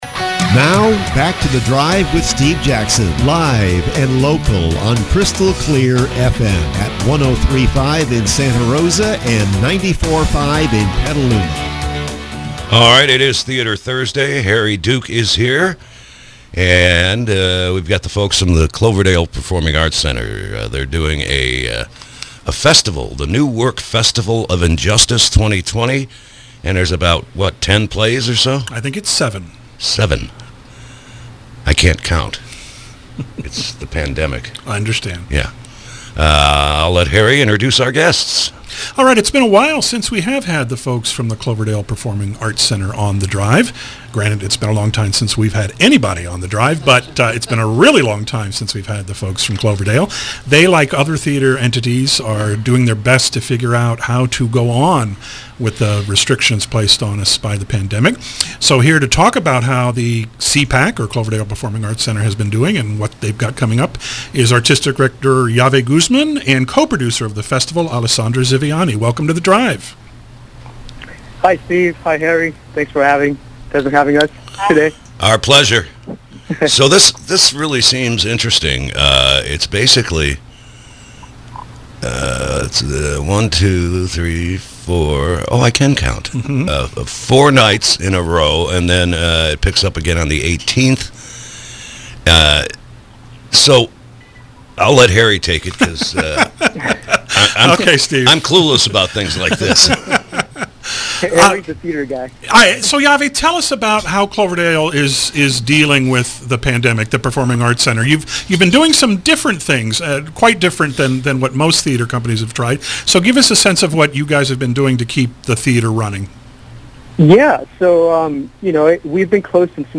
KSRO Interview – CPAC Festival of Injustice